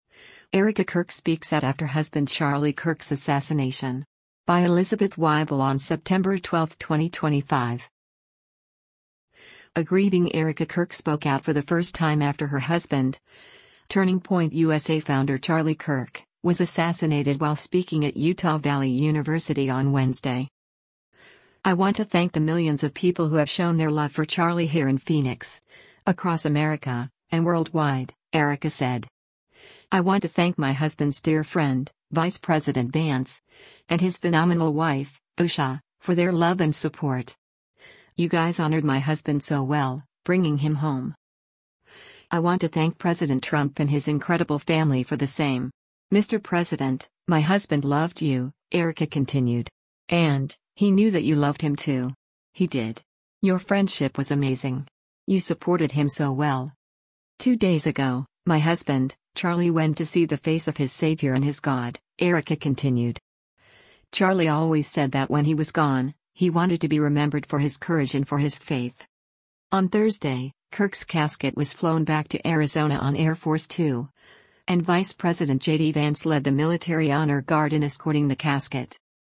A grieving Erika Kirk spoke out for the first time after her husband, Turning Point USA founder Charlie Kirk, was assassinated while speaking at Utah Valley University on Wednesday.